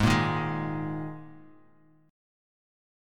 G#sus2 Chord